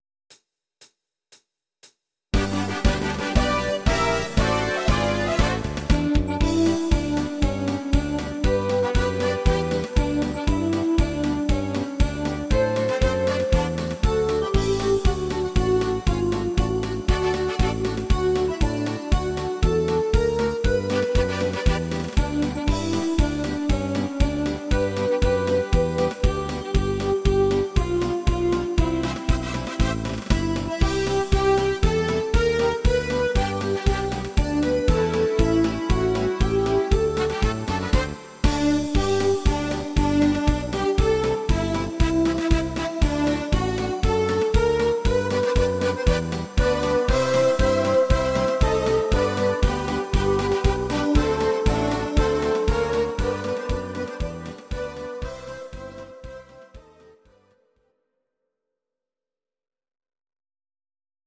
Karnevalslied aus Kölle) cover